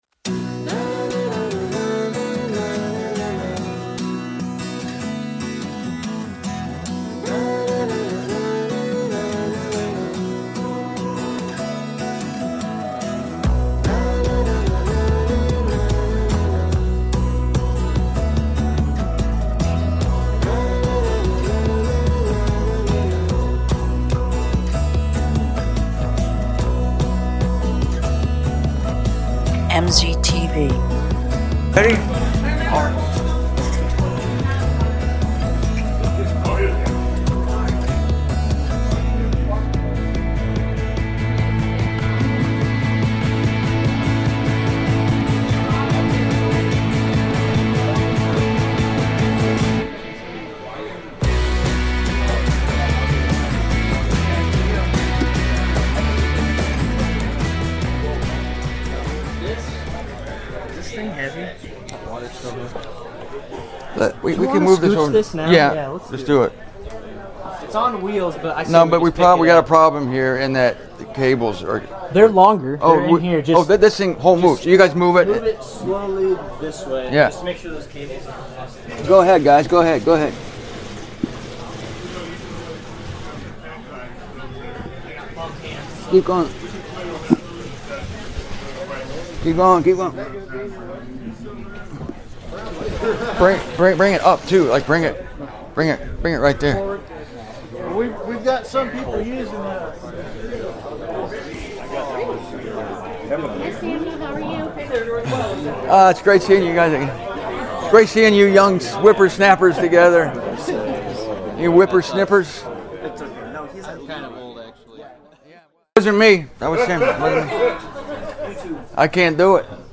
Here is a passionate teaching on the topic of faith. I delivered this talk in Des Moines, Iowa, on September 16, 2023.